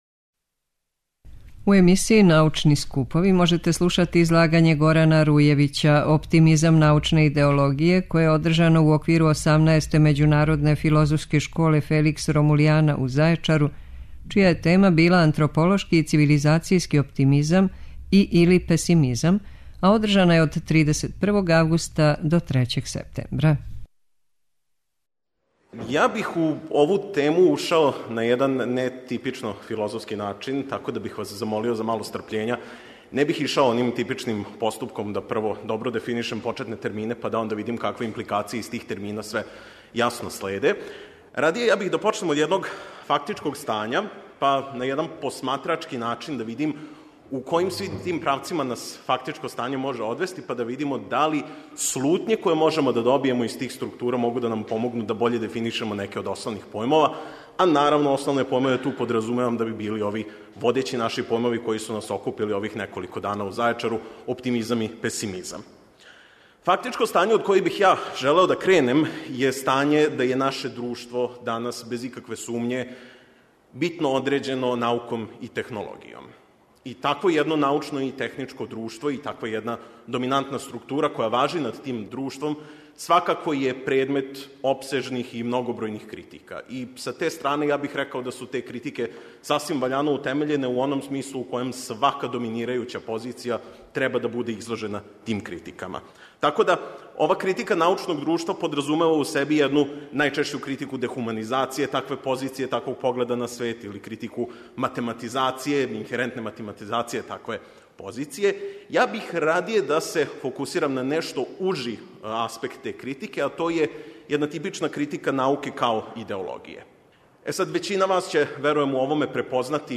које је одржано у оквиру 18. Међународне филозофске школе „Феликс Ромулијана” у Зајечару